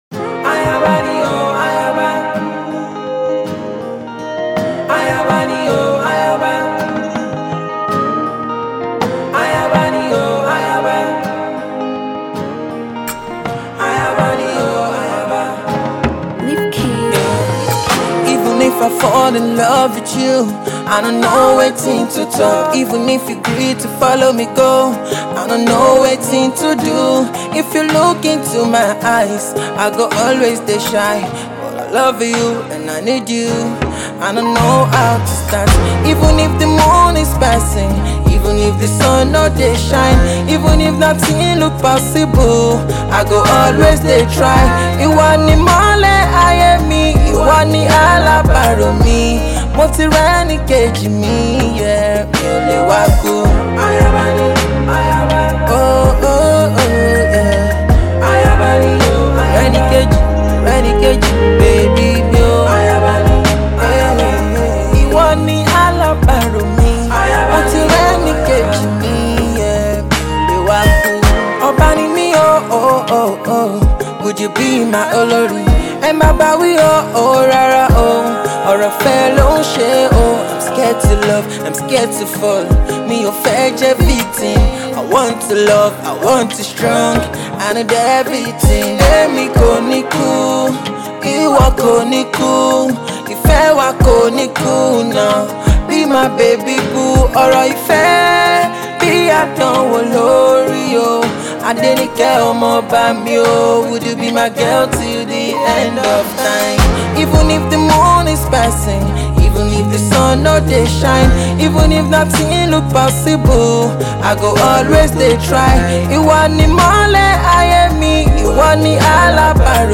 A melodious tune for all lovers.